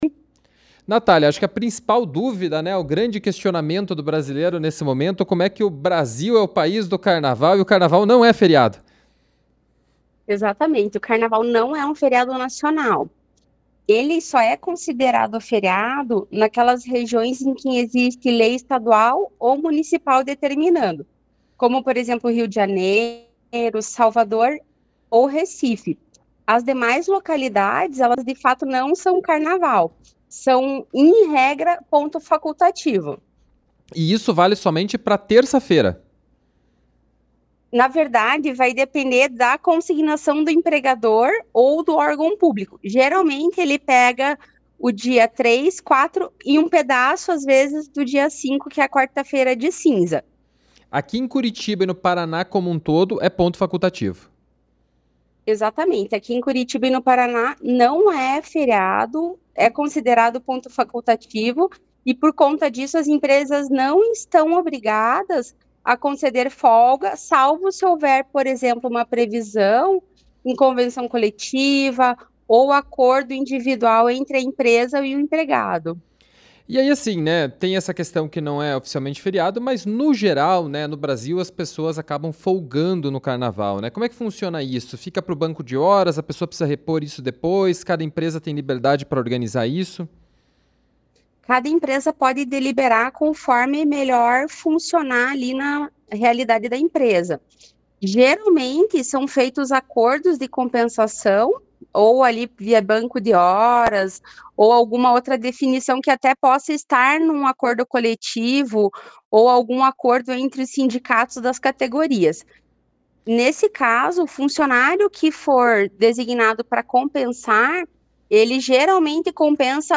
conversou com a advogada corporativa